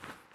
cloth_3.ogg